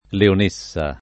vai all'elenco alfabetico delle voci ingrandisci il carattere 100% rimpicciolisci il carattere stampa invia tramite posta elettronica codividi su Facebook leonessa [ leon %SS a ] (ant. o poet. lionessa [ lion %SS a ]) s. f. — sim. il top.